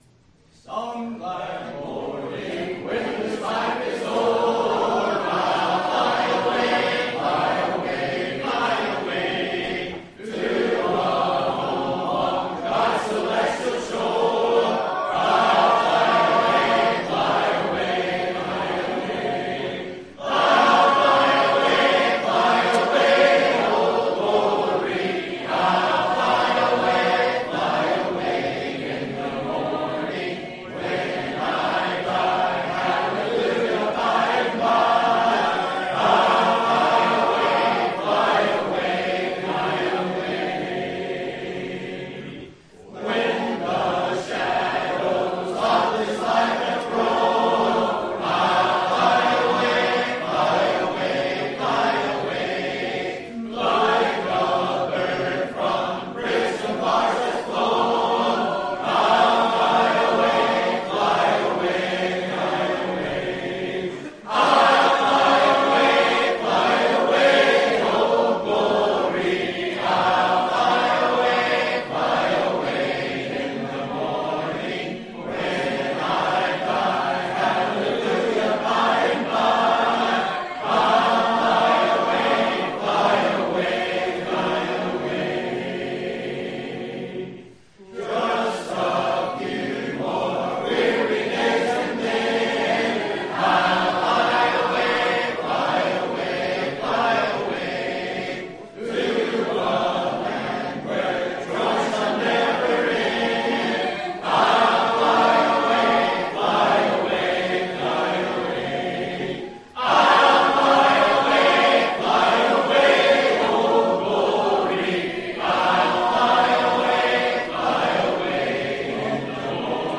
Singing
Listen I’ll Fly Away Congregational 363 Union PBC 2015 LRA 8/14/15 02:13